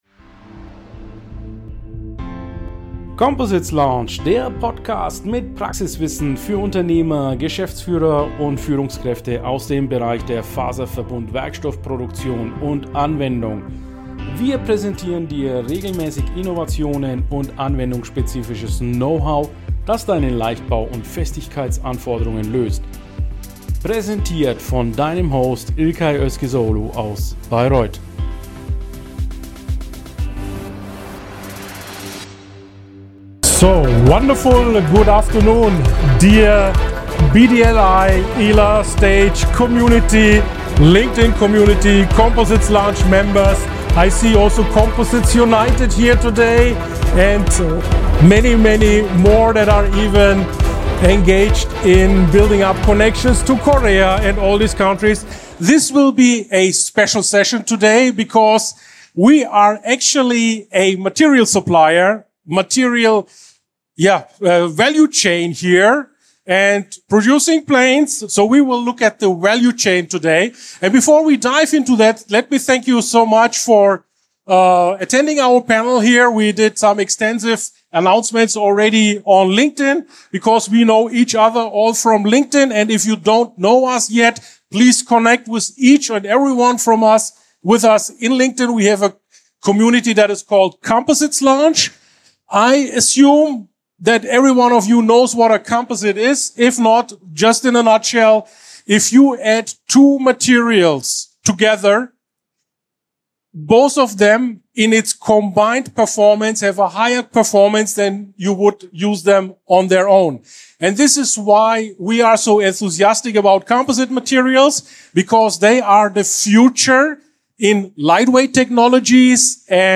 Composites Lounge powered by JEC Group with a Panel on batteries at the recent ILA.